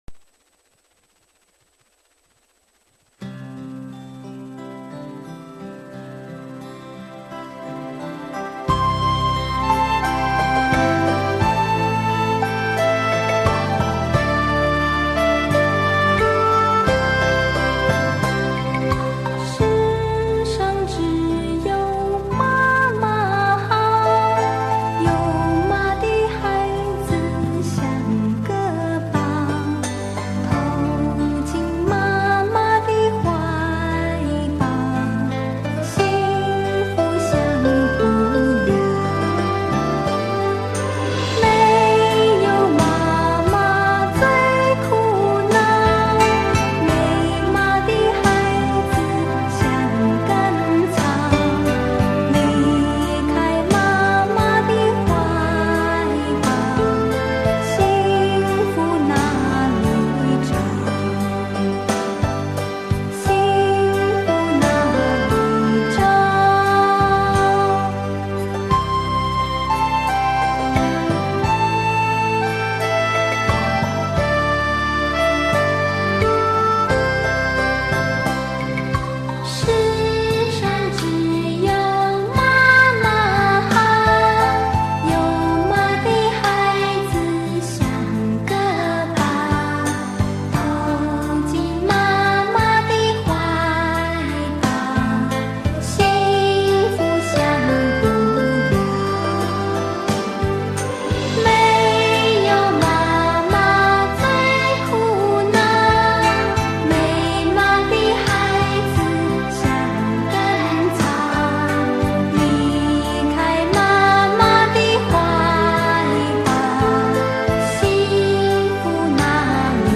Chinese Nursery Rhyme
Skor Angklung